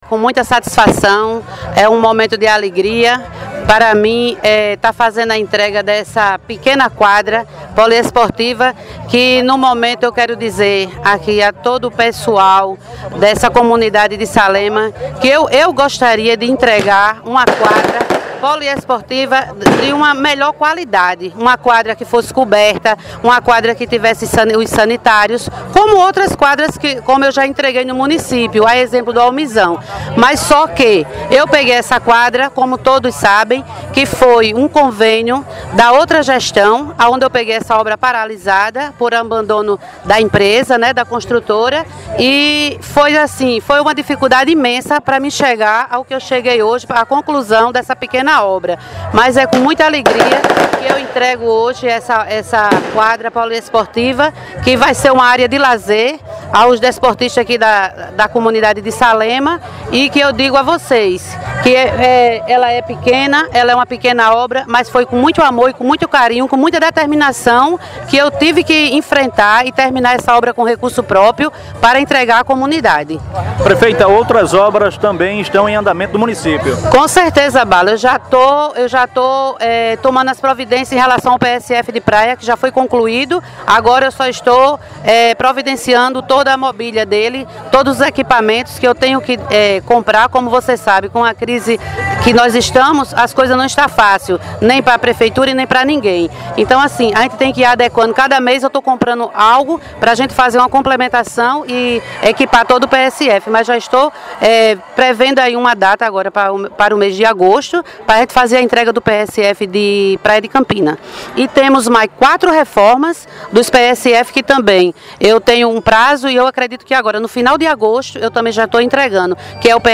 Entrevista com a prefeita Dudu de Brizola:
Entrevista-Dudu-de-Brizola.mp3